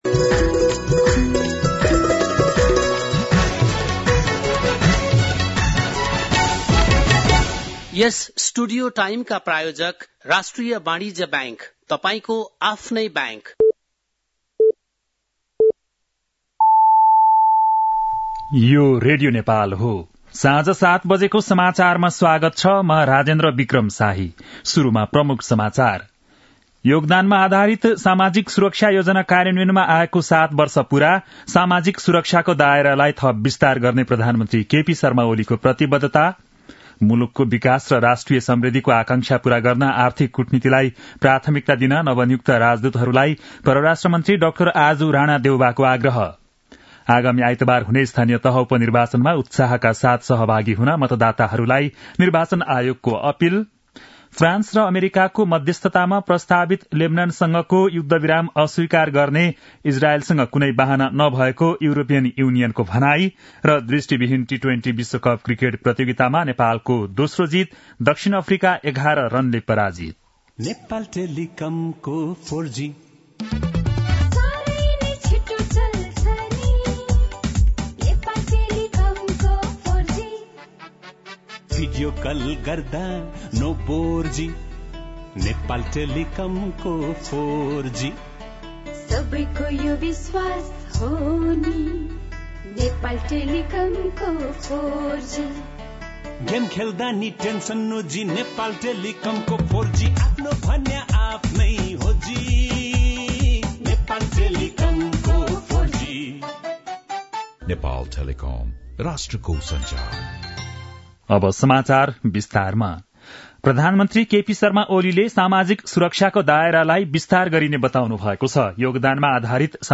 बेलुकी ७ बजेको नेपाली समाचार : १२ मंसिर , २०८१